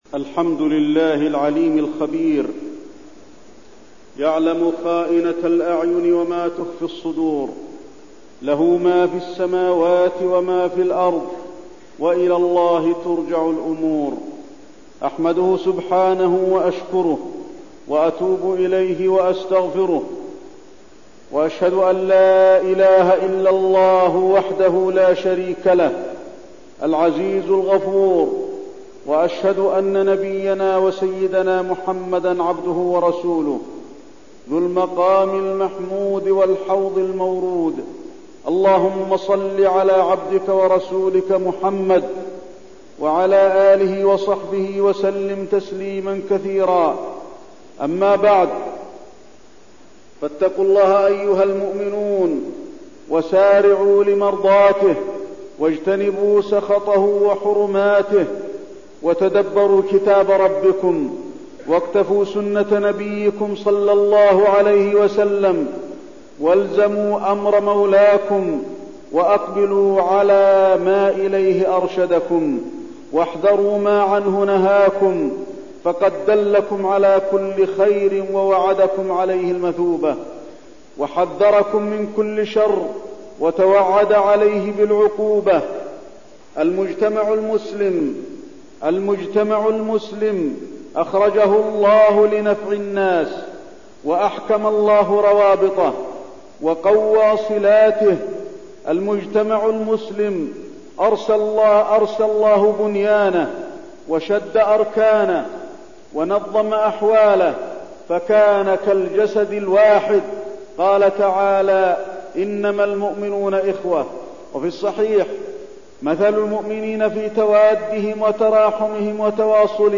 تاريخ النشر ٣ ذو القعدة ١٤١١ هـ المكان: المسجد النبوي الشيخ: فضيلة الشيخ د. علي بن عبدالرحمن الحذيفي فضيلة الشيخ د. علي بن عبدالرحمن الحذيفي التثبت في الأخبار